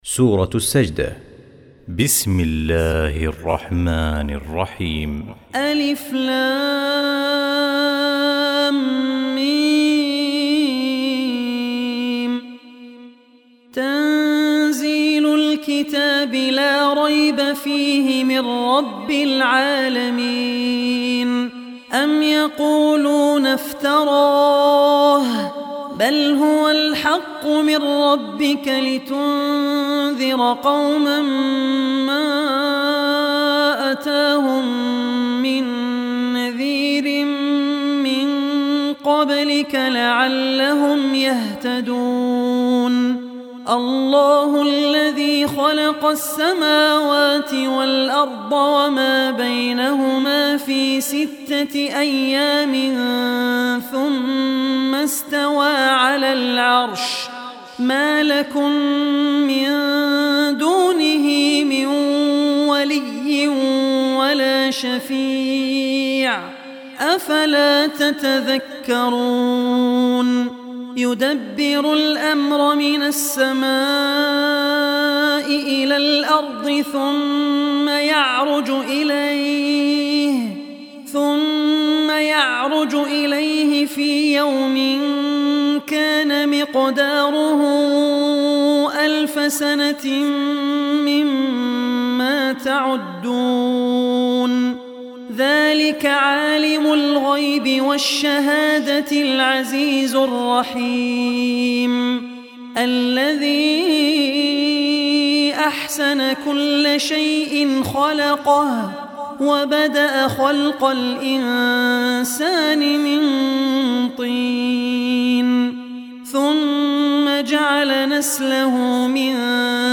Surah As-Sajdah Recitation by Abdur Rehman Al Ossi
Surah As-Sajdah, listen online mp3 tilawat / recitation in the voice of Sheikh Abdur Rehman Al Ossi.